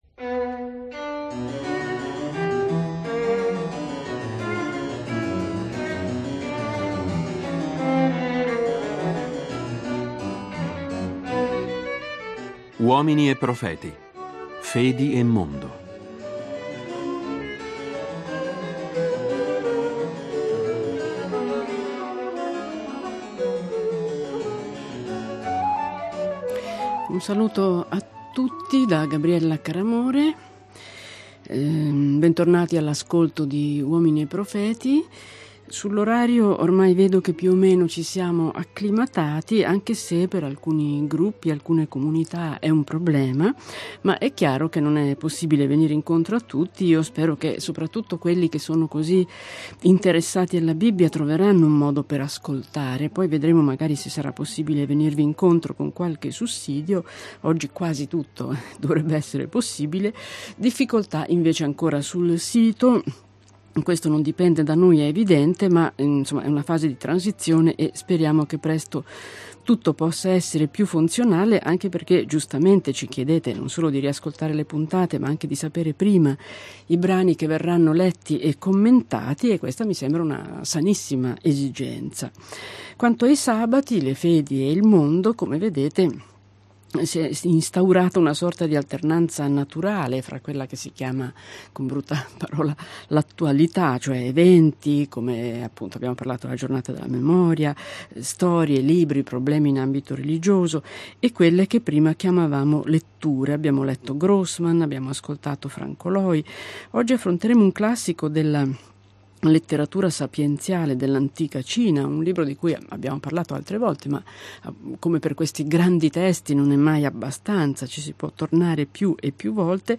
Guido Tonelli | L’invisibile meraviglia del vuoto (Festival della Mente 2025) – Video